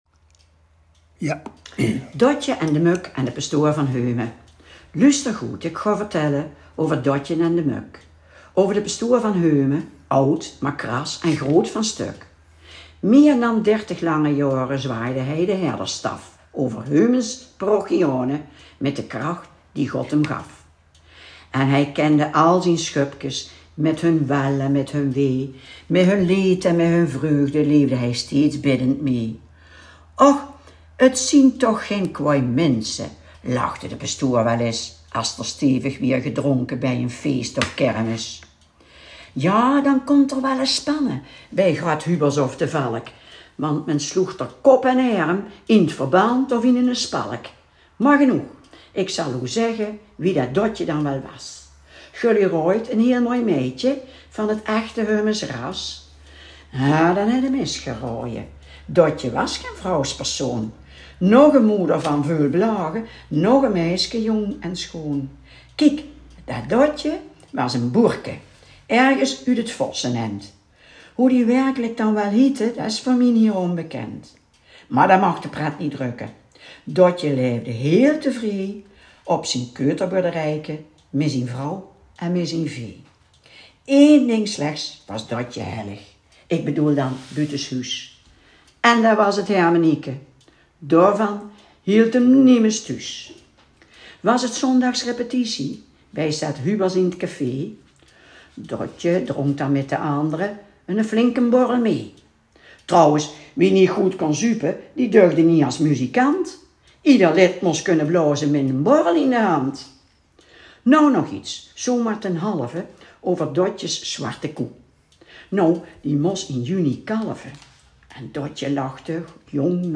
vertelling